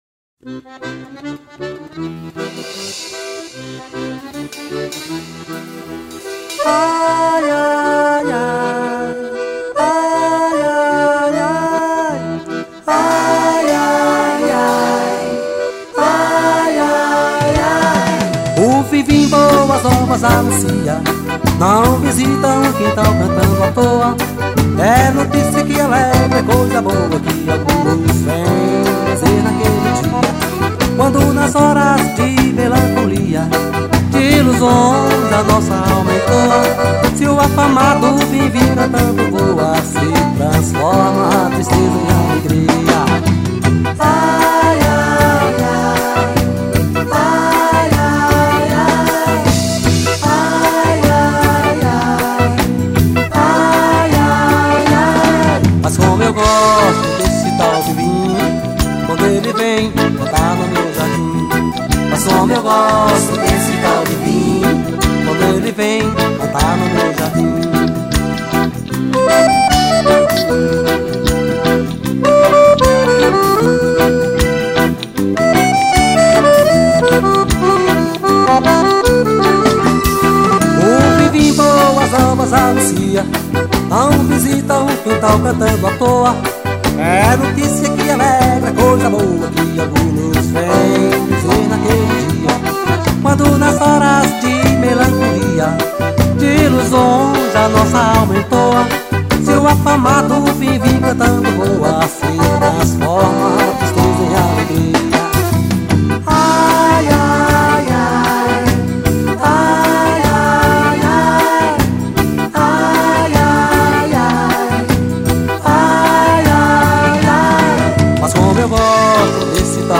2137   02:42:00   Faixa:     Forró